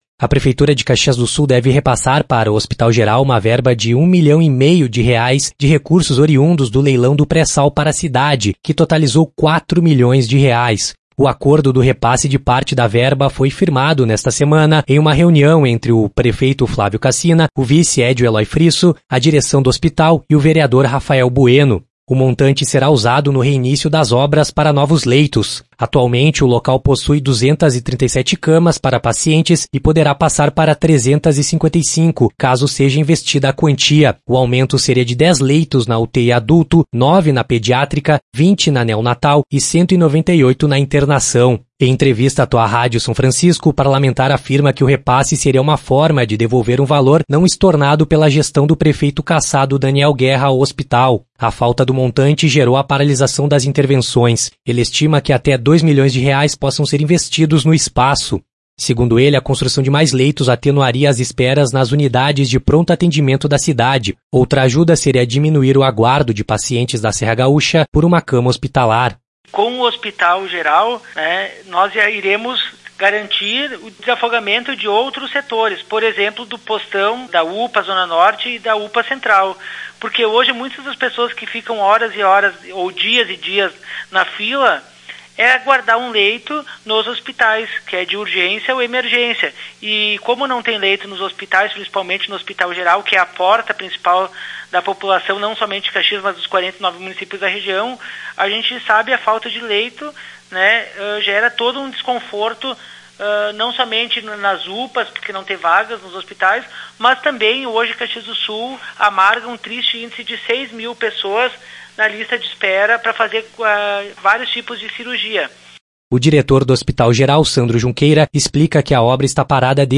Em entrevista à Tua Rádio São Francisco, o parlamentar afirma que o repasse seria uma forma de devolver um valor não estornado pela gestão do prefeito cassado Daniel Guerra (Republicanos) ao hospital.